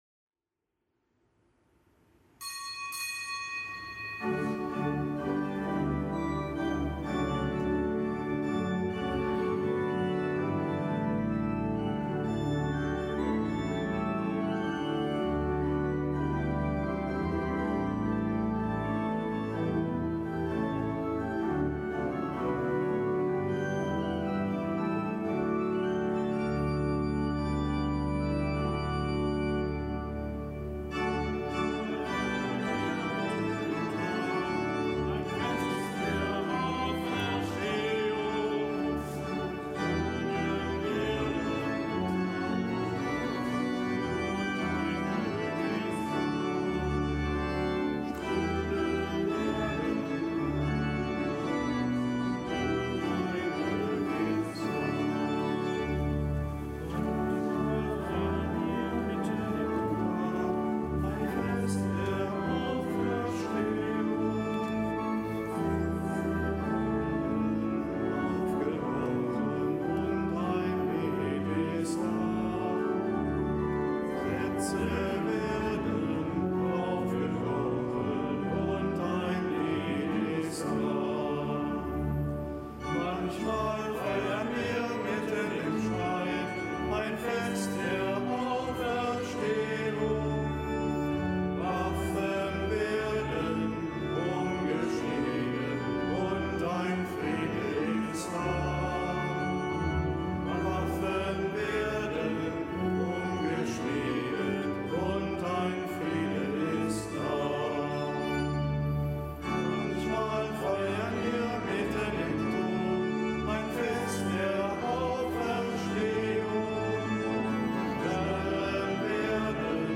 Kapitelsmesse aus dem Kölner Dom am Dienstag der 14.